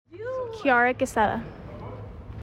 ⇓ Name Pronunciation ⇓